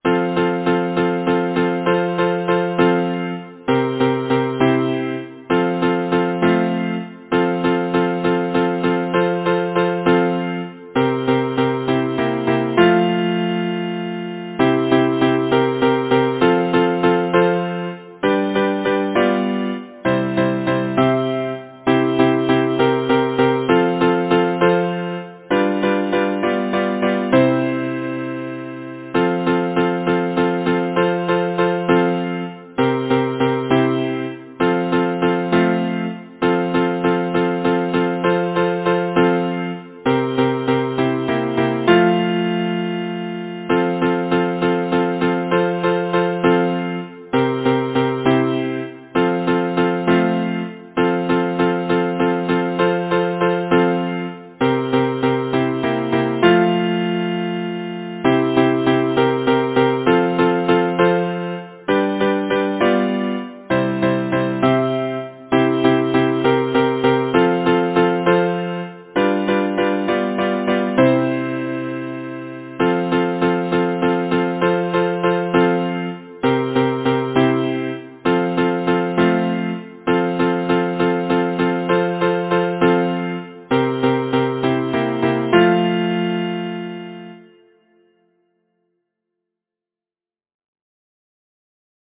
Title: Boating Song Composer: Horatio Richmond Palmer Lyricist: Number of voices: 4vv Voicing: SATB Genre: Secular, Partsong
Language: English Instruments: A cappella